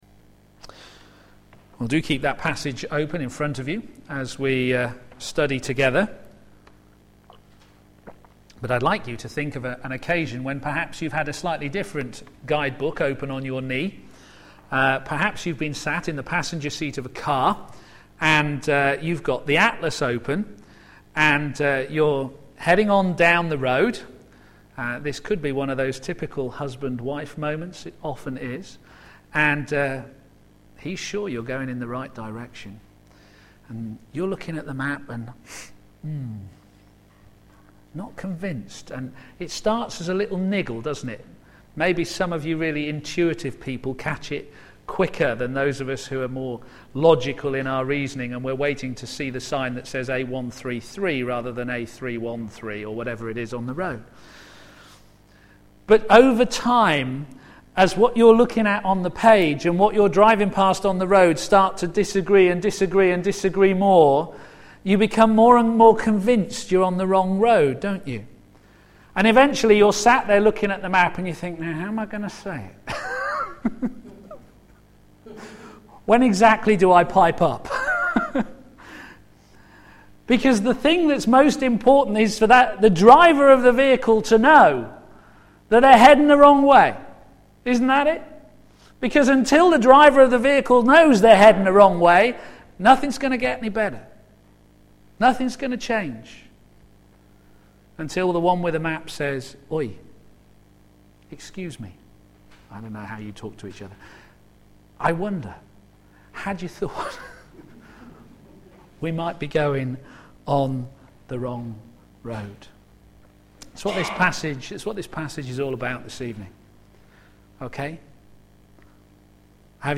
p.m. Service
Sermon